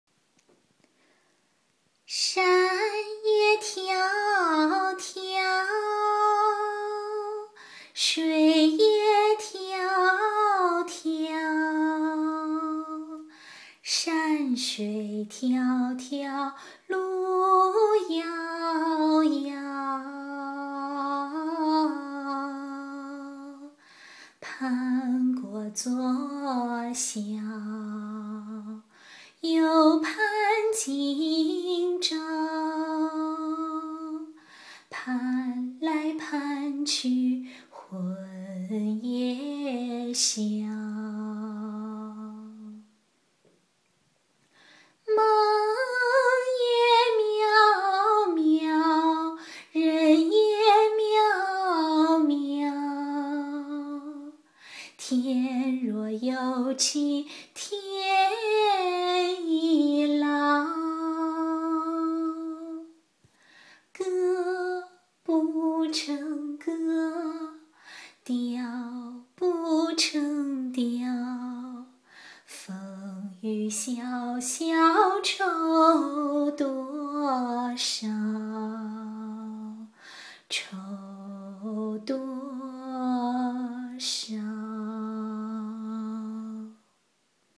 这清唱太牛了~